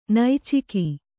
♪ naiciki